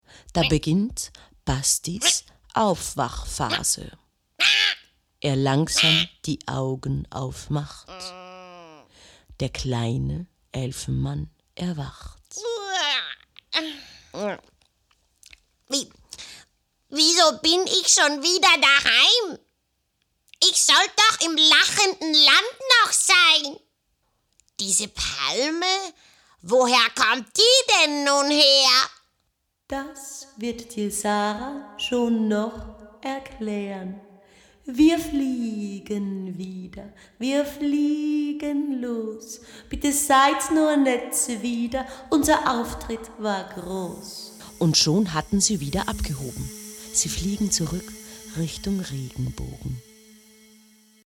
Funk- und TV Werbung Hörspiel Doku Synchro Voice Over
Hörspiel "Das Einhorn & der Elfenmann"